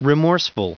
Prononciation du mot remorseful en anglais (fichier audio)
Prononciation du mot : remorseful